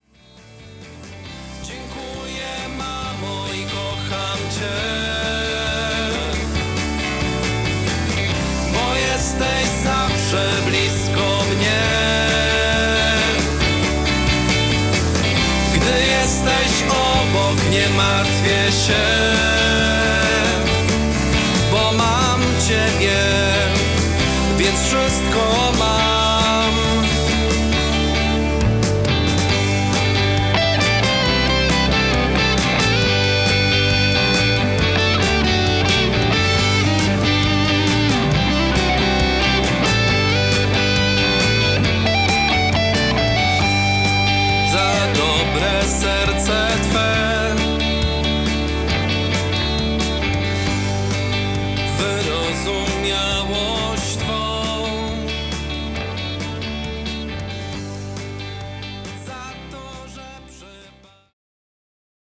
Wzruszająca piosenka dla kochanej mamy